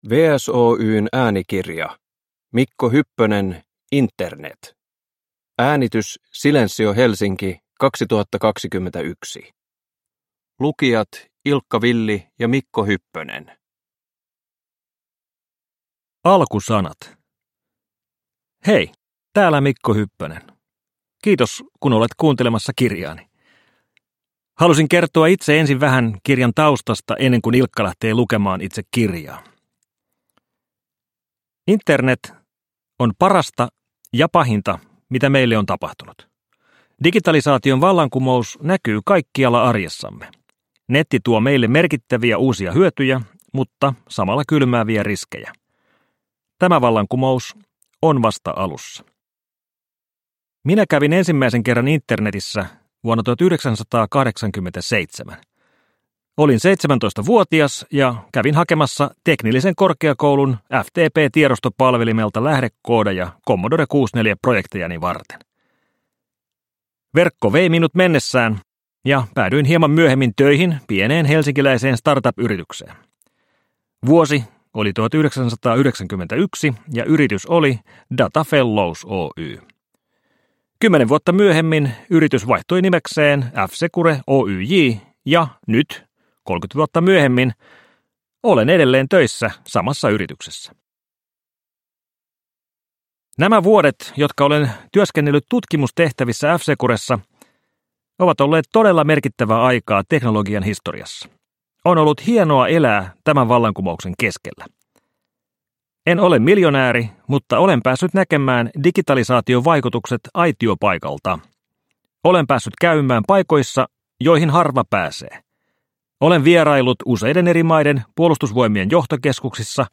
Produkttyp: Digitala böcker
Uppläsare: Ilkka Villi